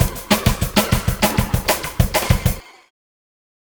Swinging 60s Drumz Vrtn.wav